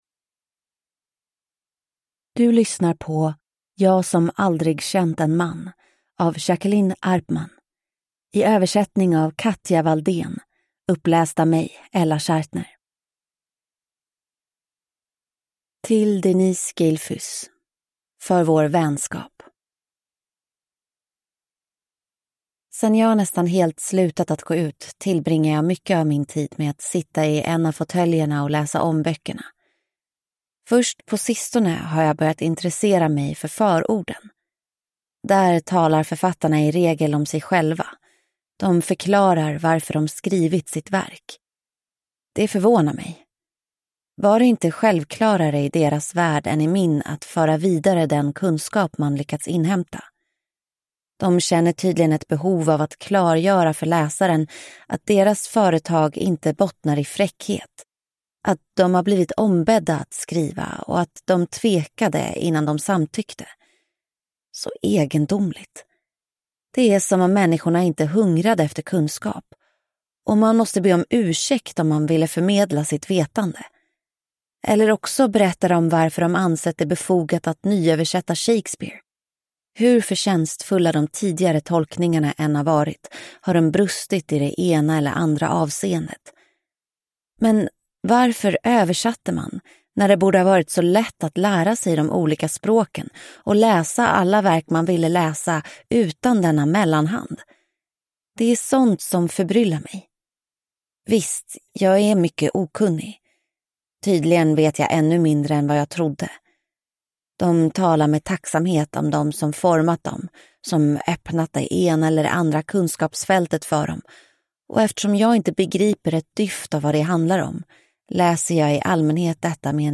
Jag som aldrig känt en man – Ljudbok